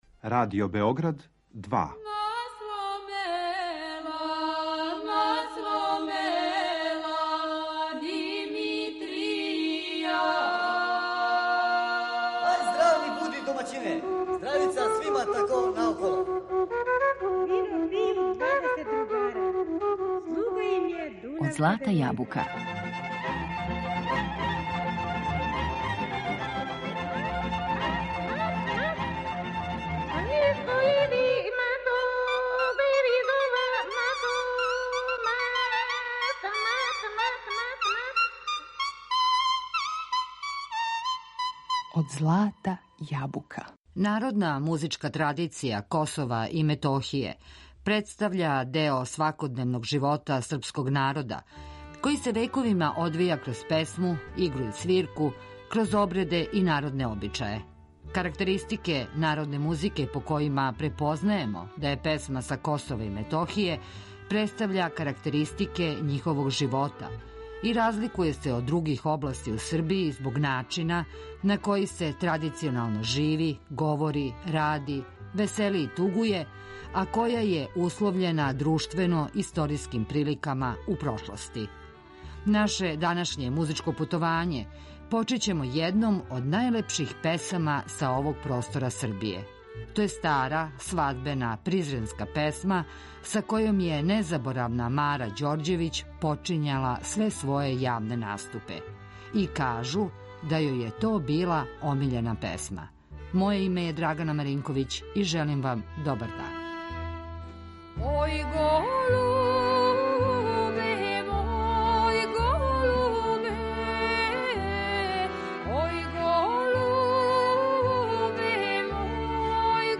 Народна музичка традиција Косова и Метохије